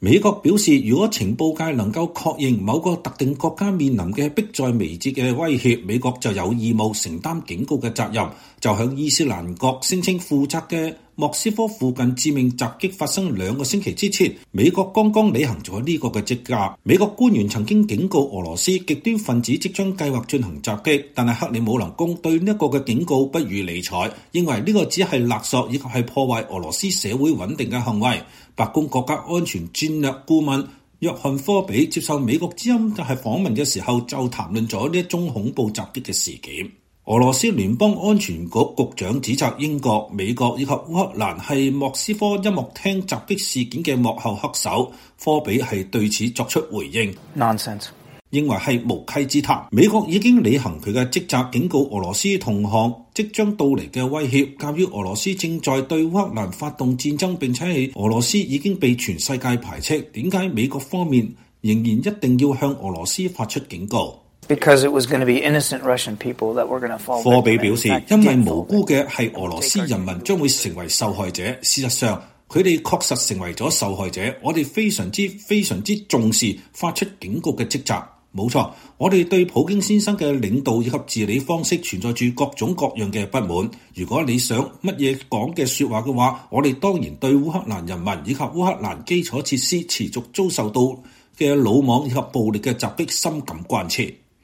VOA專訪白宮國安會發言人：華盛頓曾緊急警告俄羅斯可能發生恐怖攻擊
白宮國家安全戰略溝通顧問約翰·科比（John Kirby）同美國之音（VOA）談論了這起恐怖攻擊事件。